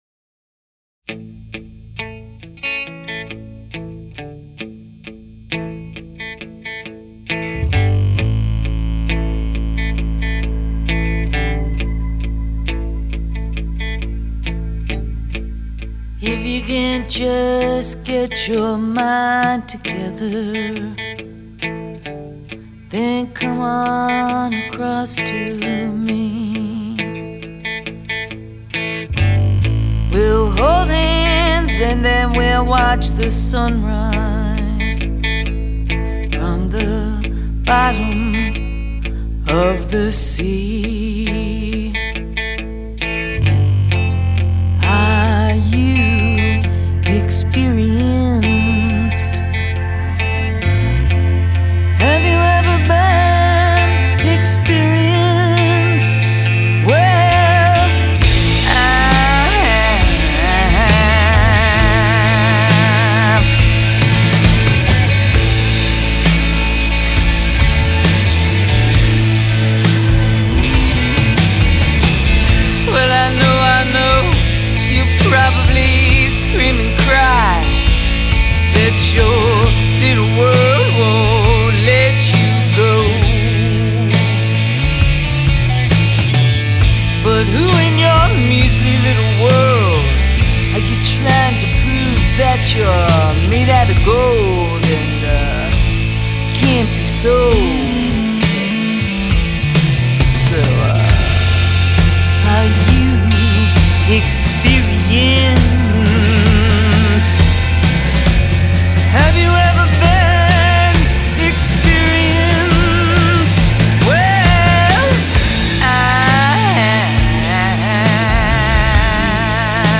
Cover-Album